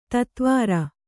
♪ tatvāra